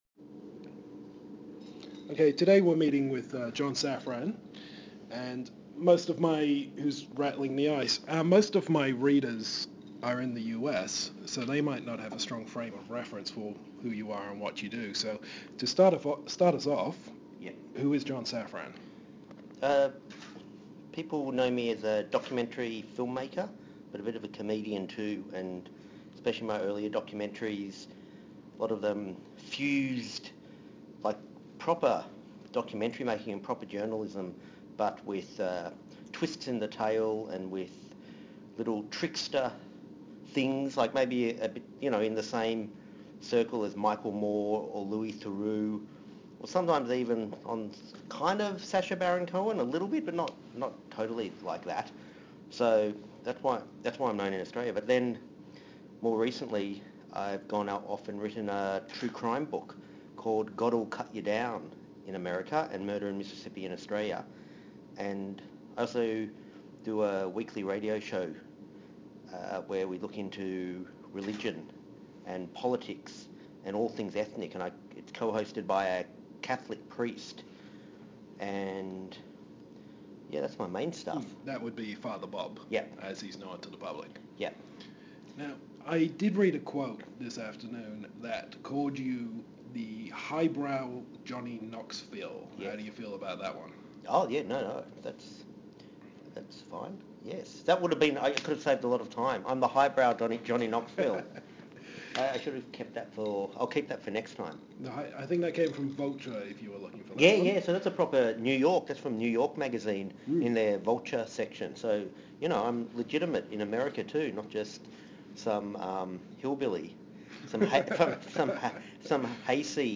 Exclusive Interview: John Safran